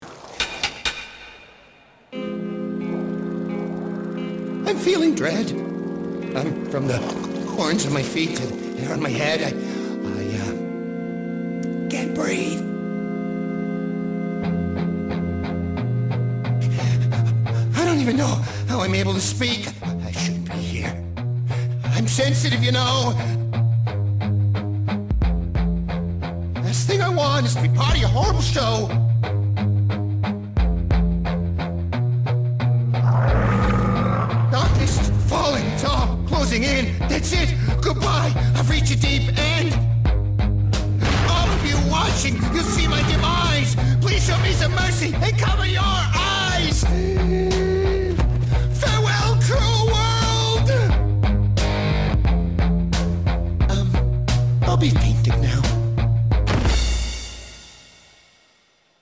rip his song performance from it from episode 20